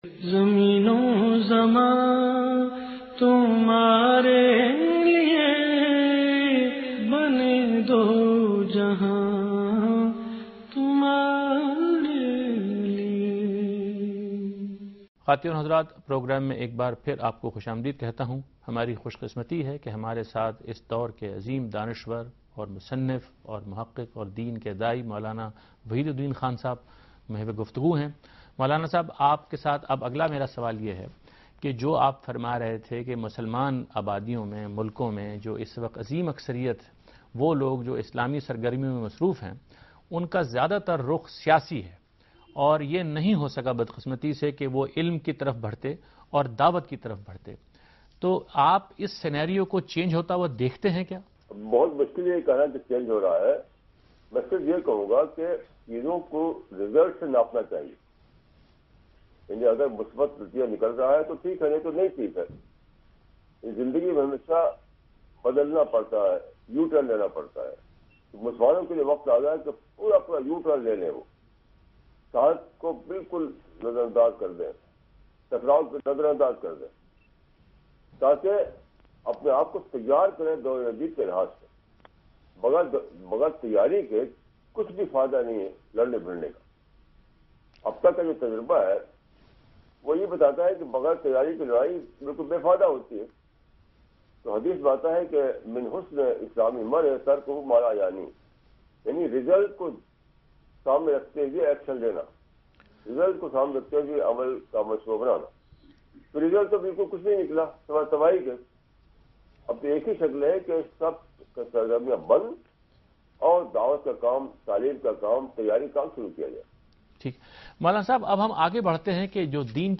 Special Episode of program "Deen o Daanish" with Mowlana Wahidud Din from India.
دنیا ٹی وی کےاس پروگرام میں مولانہ وحید الدین خان "نبی اکرم کی زندگی اور ہماری ذمہ داریاں" کے متعلق ایک سوال کا جواب دے رہے ہیں